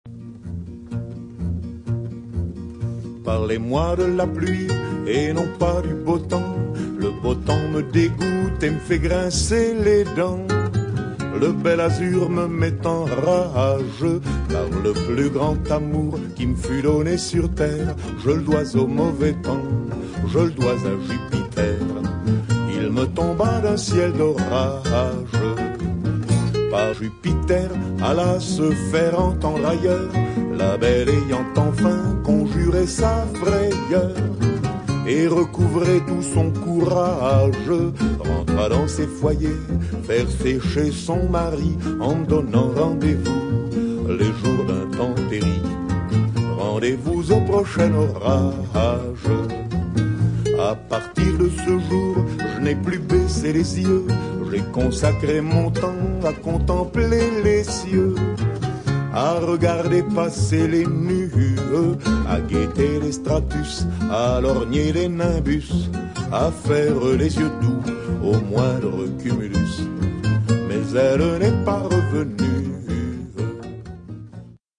Jeudi le 27 août 2009, nous nous réunissions au Théâtre de la Dame de Cœur pour célébrer le 30e anniversaire de l’AREQ du Vieux -Longueuil.
L’équipe du comité de la condition de la femme, nommée pour l’organisation de cette fête, avait choisi de souligner en chansons cet événement.